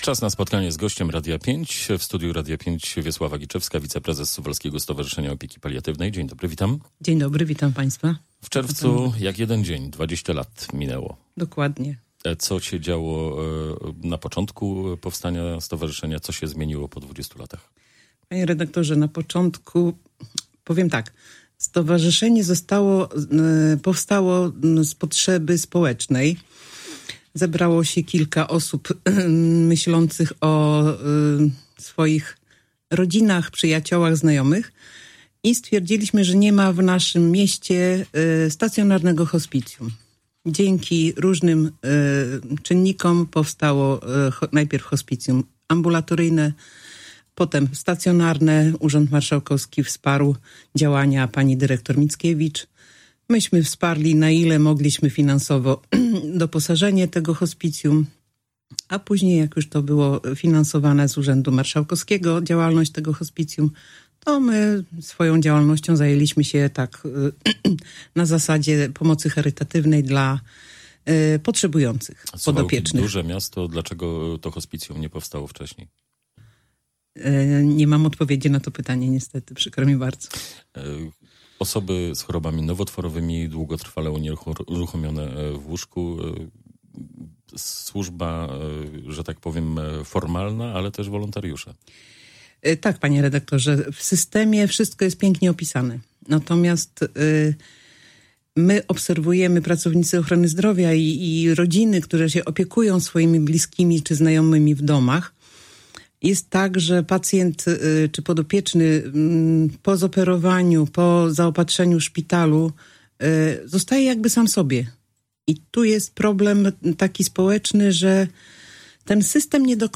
Cała rozmowa poniżej: